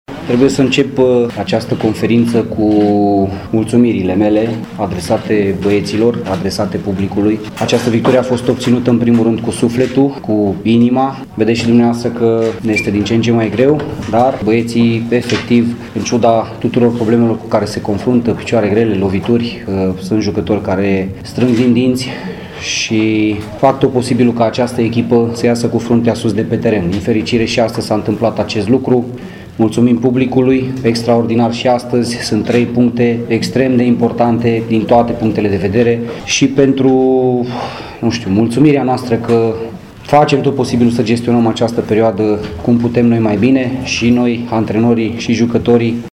La final de joc, antrenorul arădenilor, Balint Laszlo, și-a lăudat jucătorii și suporterii: